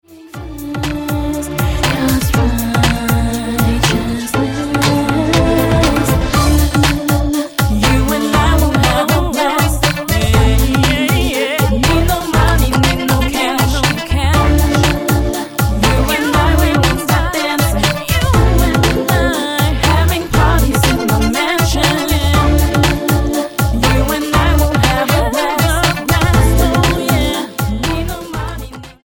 R&B gospel singer
Dance/Electronic